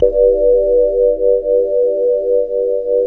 PAD_Bass Pad
PAD_Bass Pad.wav